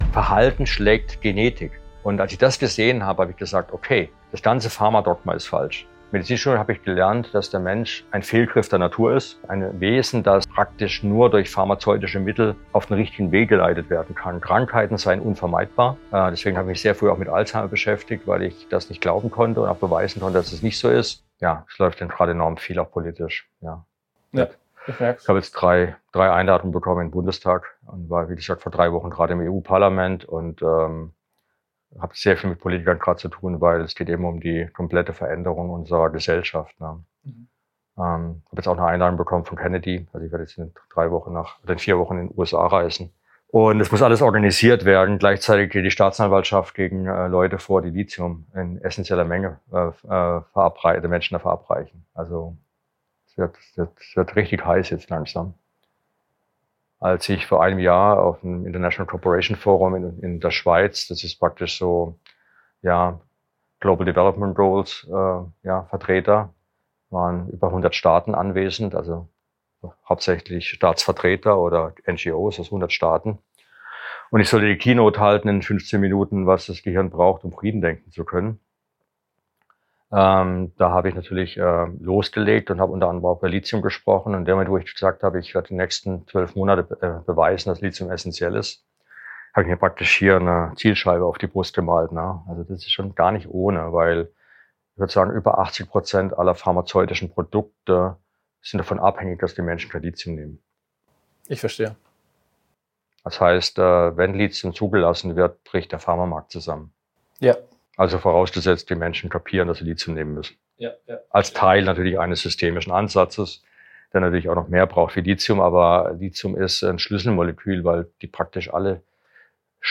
Warum das wichtig ist: Dieses Gespräch ist Teil der Rebellion der Gesundheitsbranche.